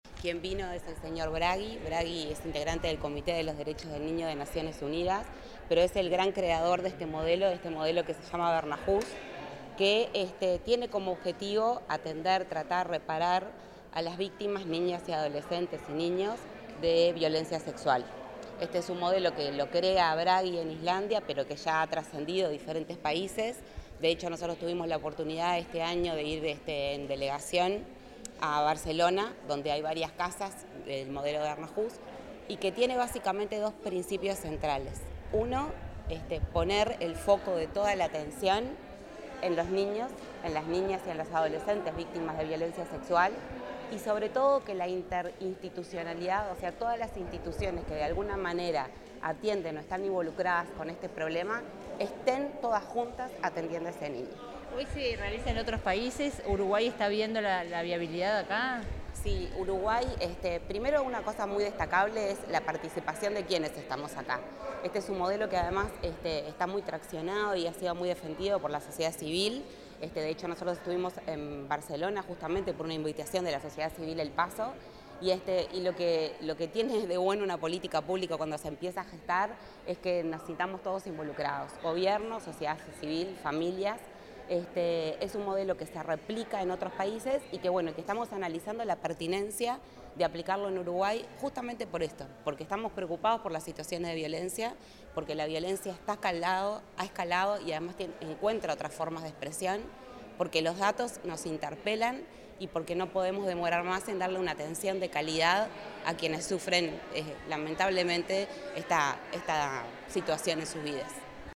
Declaraciones de la presidenta del INAU, Claudia Romero